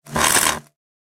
lwforce_field_zap.ogg